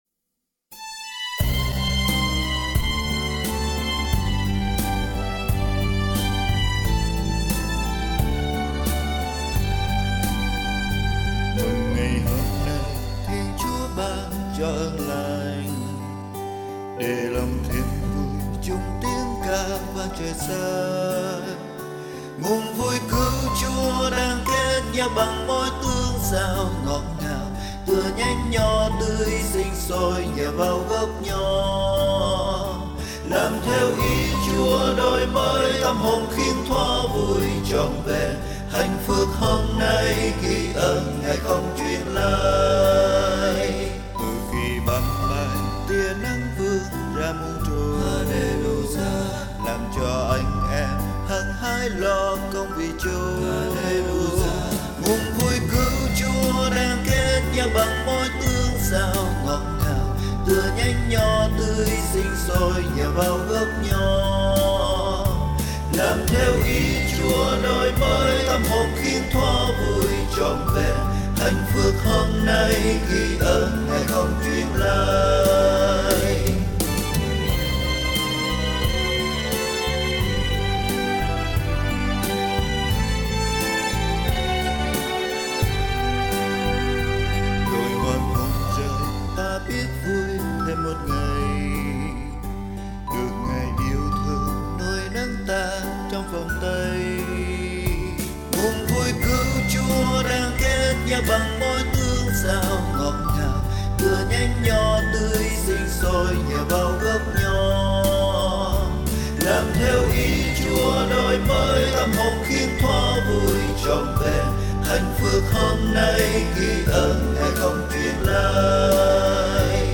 Bài hát Xuân: VUI MỪNG TRỌN VẸN
Nhạc Thánh Sáng Tác Mới